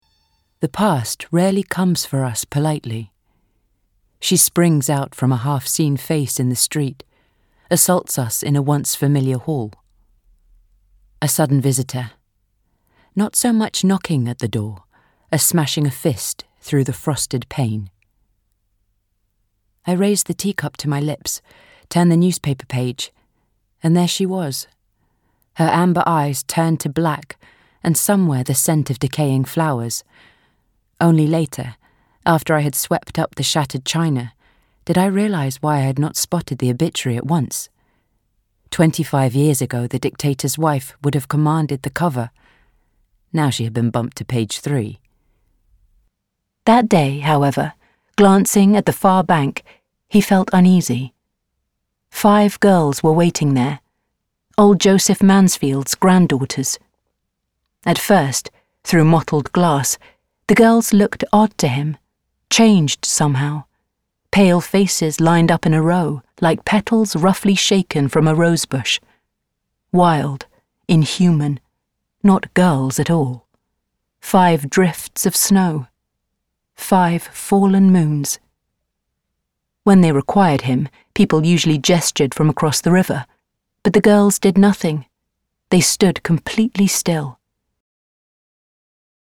With a warm and natural read, OLIVIA VINALL can be seen in Queens of Mystery and more recently, the role of Stella in A Beautiful Curse.
Olivia is a great pick for an energetic and experienced reader to spark life into any character.